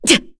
FreyB-Vox_Attack2_kr.wav